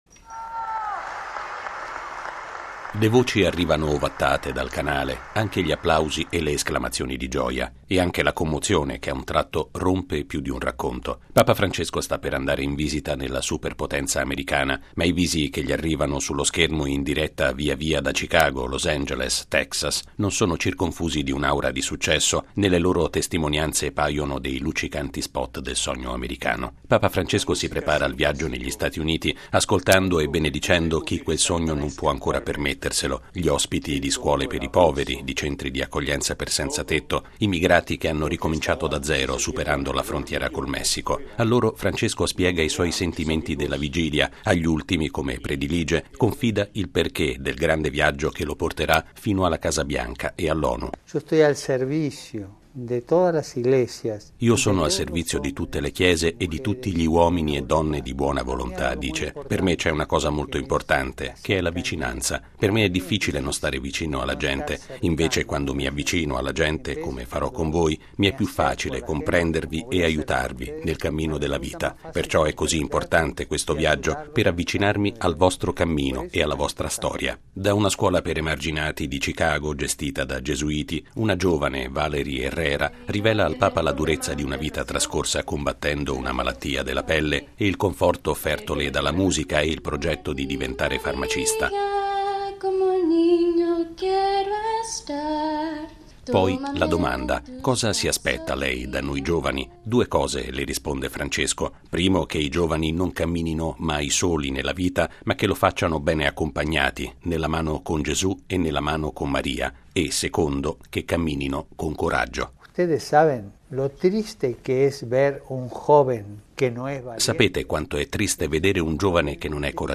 Durante una videoconferenza trasmessa ieri sera (in Italia era notte) dalla rete televisiva statunitense “Abc”, Papa Francesco ha spiegato con quale animo si appresti al viaggio apostolico che dal 22 al 27 settembre prossimi lo porterà in America, in particolare a Philadelphia per l’Incontro mondiale delle famiglie.
Le voci arrivano ovattate dal canale, anche gli applausi e le esclamazioni di gioia.